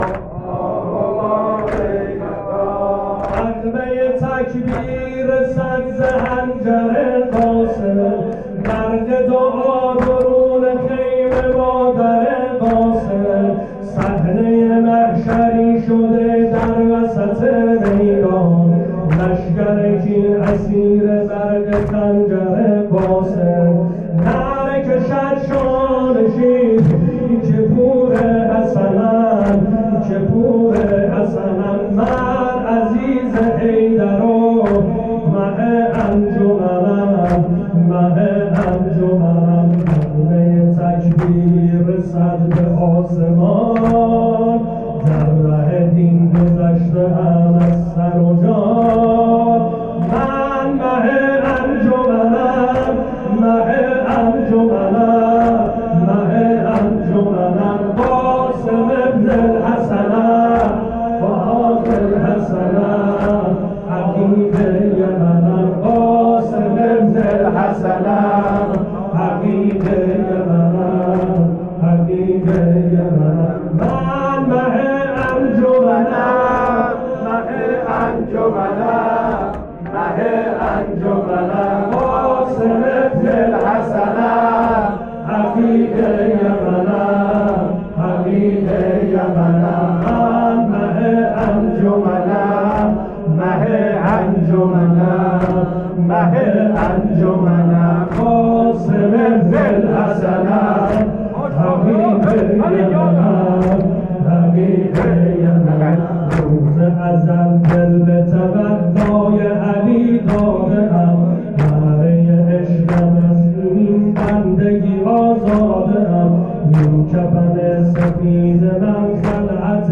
شب پنجم محرم ۱۴۰۱
مداحی